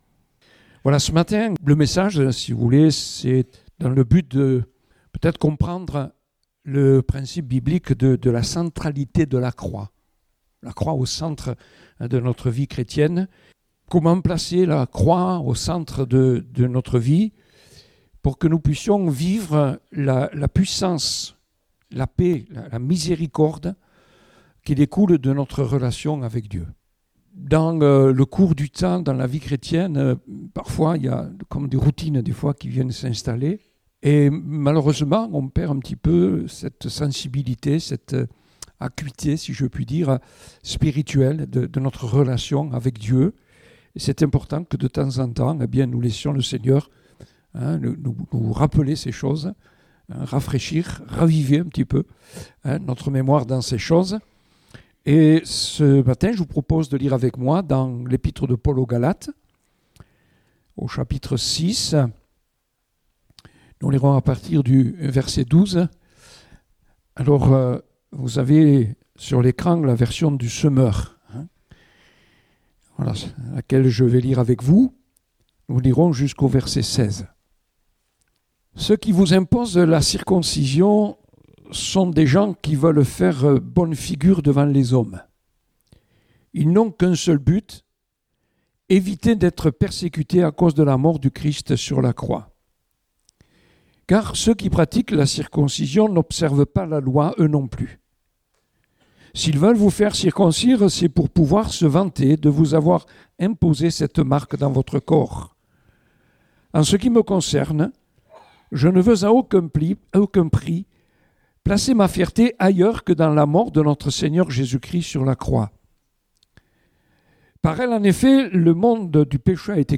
Date : 10 février 2019 (Culte Dominical)